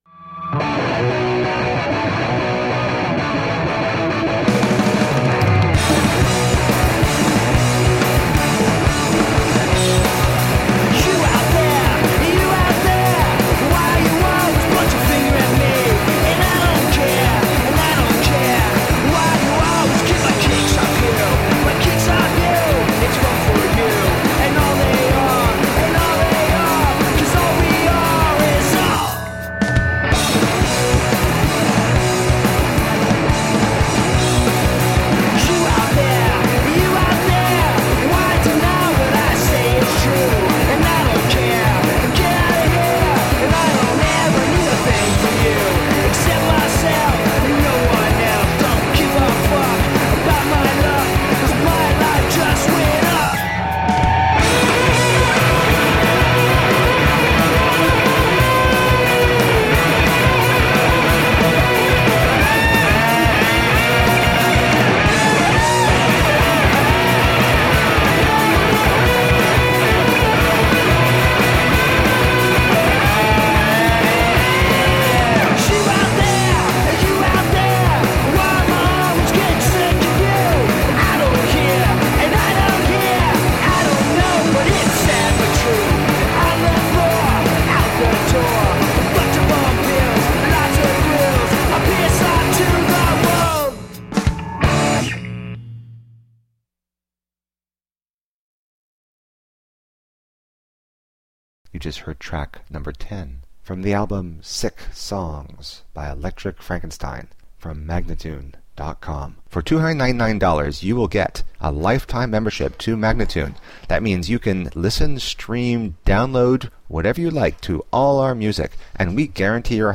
High energy punk rock & roll.
Tagged as: Hard Rock, Punk, Rock, High Energy Rock and Roll